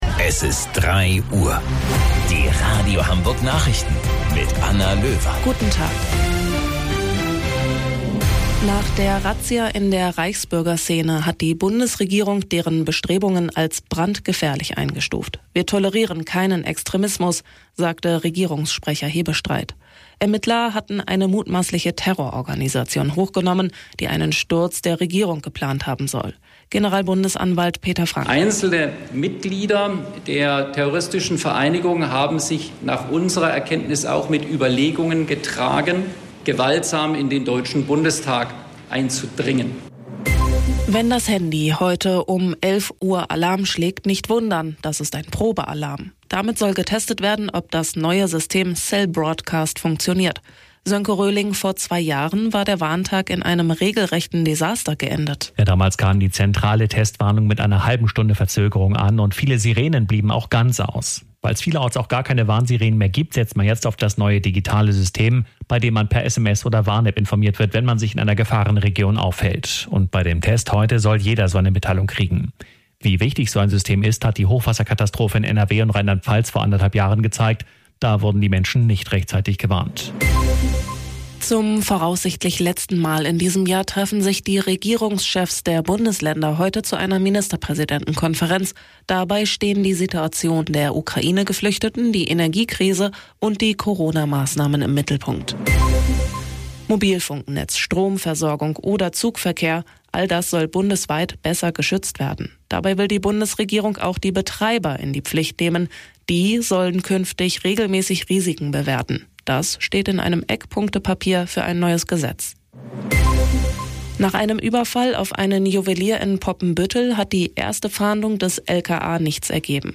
Radio Hamburg Nachrichten vom 24.09.2022 um 12 Uhr - 24.09.2022